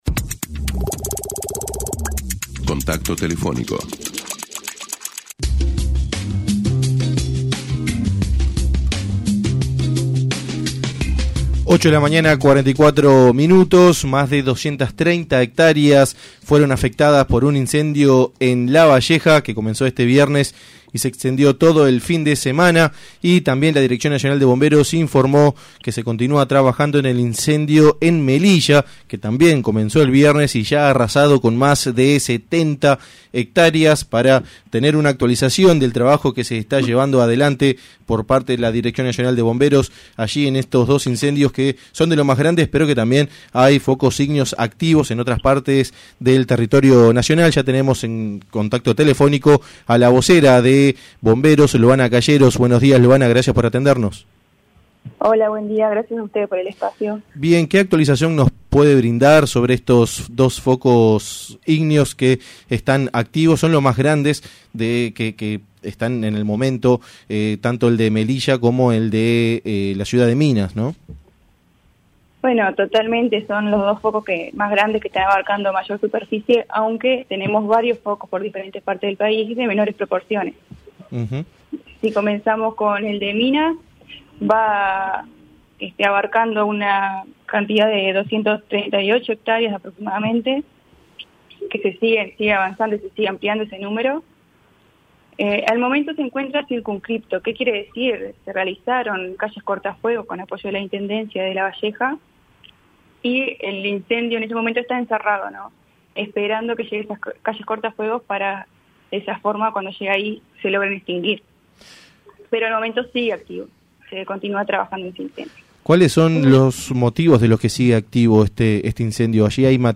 se refirió en una entrevista con 970 Noticias